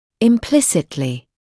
[ɪm’plɪsɪtlɪ][им’плиситли]полностью; безоговорочно; неявно; косвенным образом